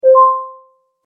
popup_open.mp3